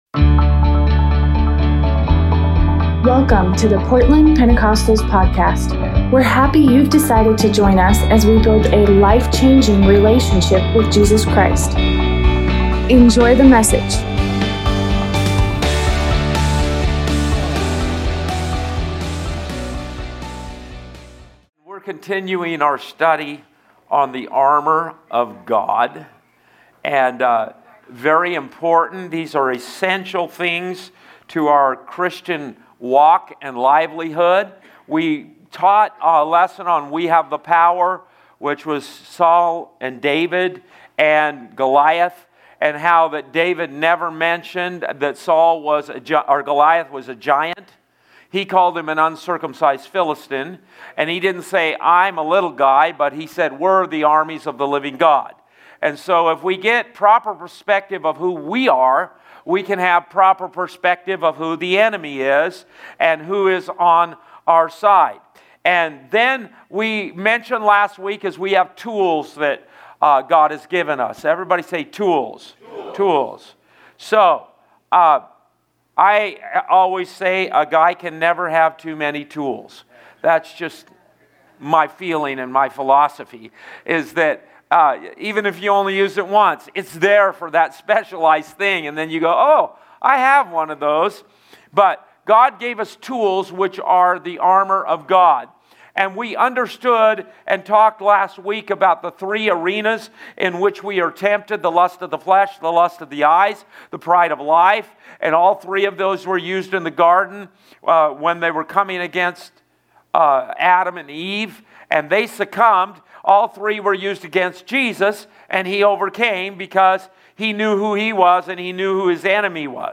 Tuesday Bible study